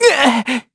Evan-Vox_Damage_kr_03.wav